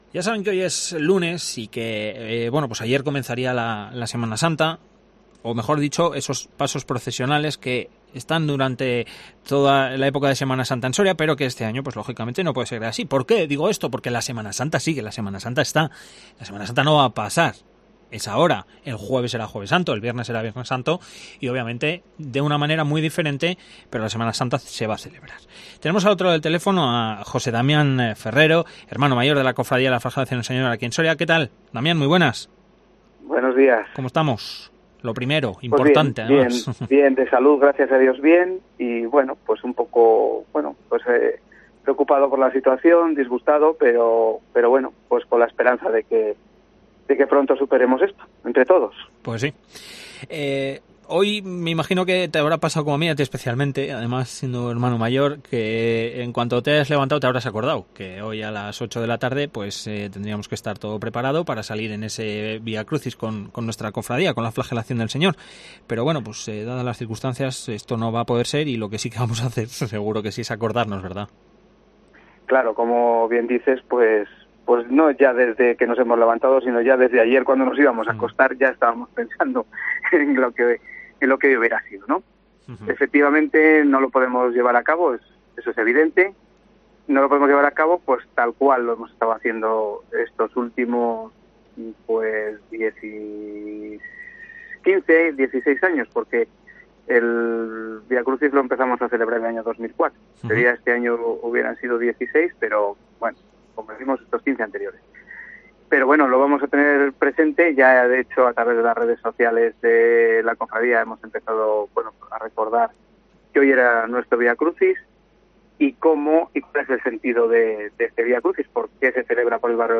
ENTREVISTA Cofradía de la Flagelación del Señor, Soria